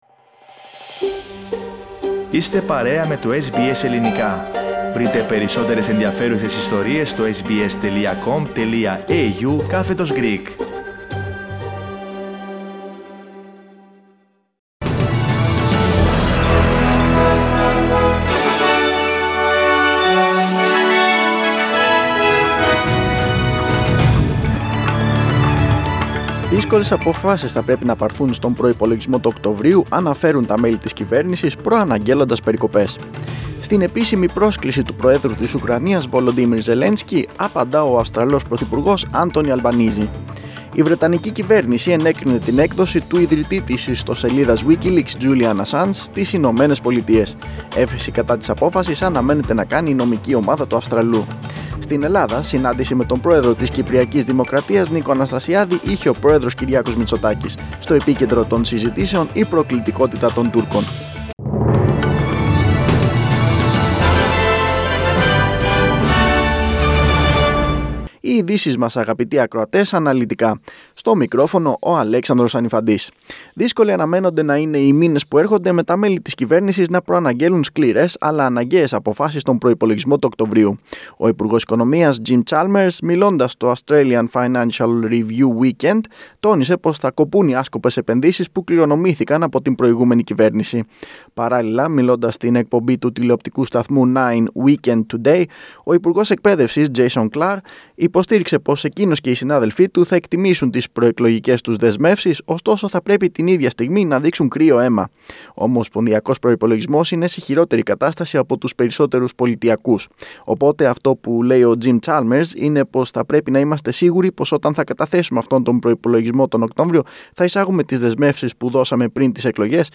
Δελτίο Ειδήσεων 18.6.2022
News in Greek. Source: SBS Radio